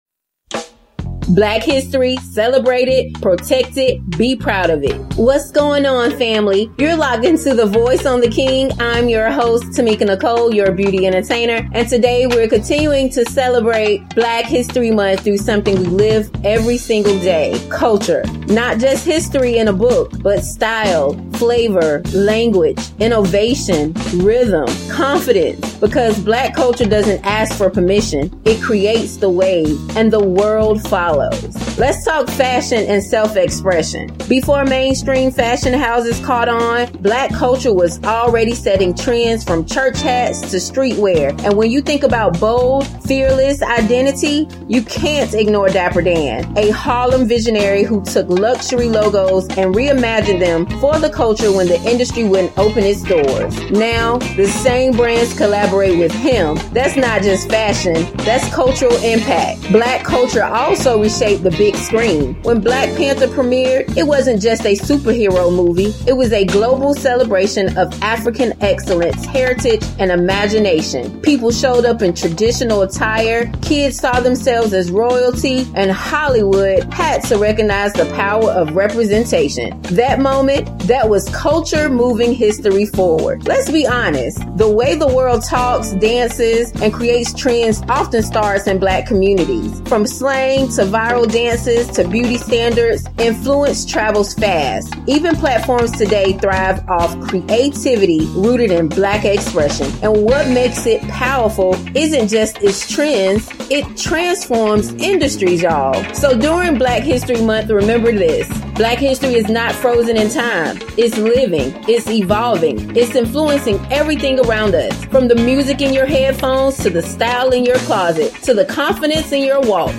The Voice on The King a quick conversation where local and national leader, business owners and brands, artists and authors share their voices and stories with the world!